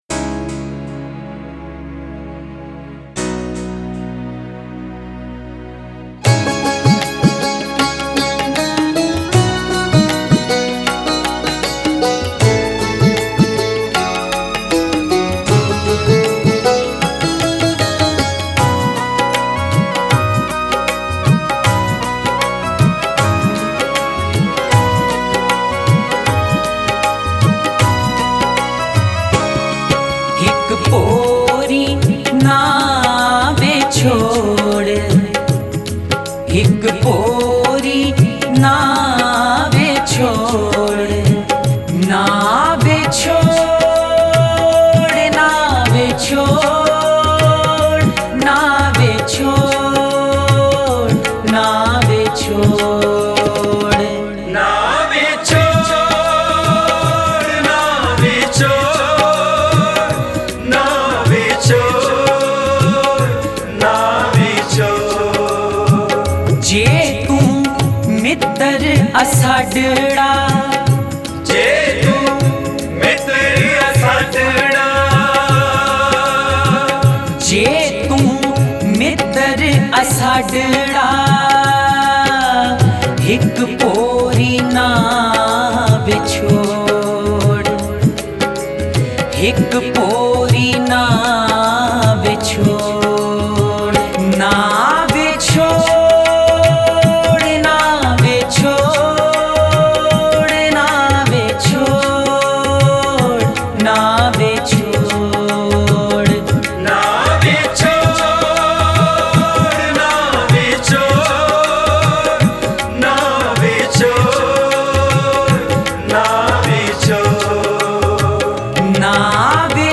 Shabad Gurbani Kirtan Album Info